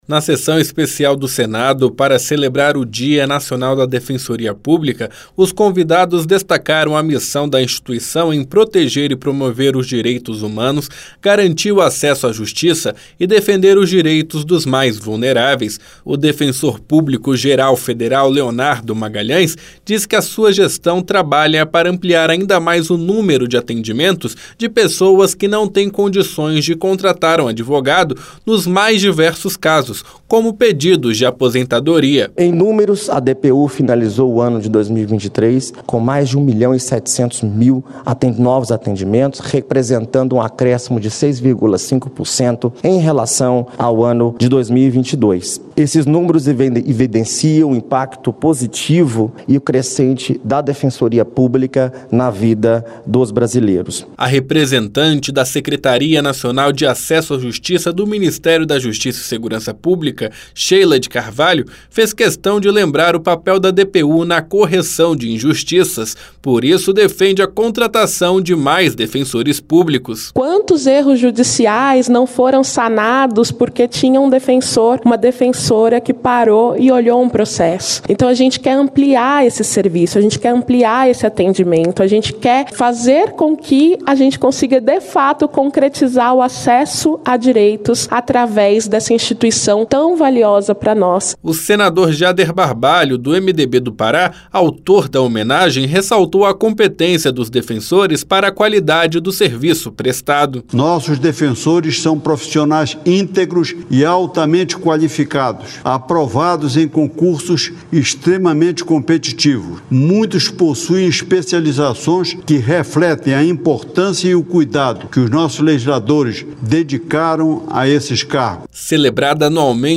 O Plenário do Senado celebrou o Dia Nacional da Defensoria Pública, comemorado em 19 de maio. Durante a sessão especial, os convidados destacaram o papel fundamental da instituição na defesa dos direitos humanos. O defensor público-geral federal, Leonardo Magalhães, ressaltou o aumento dos atendimentos realizados pela DPU.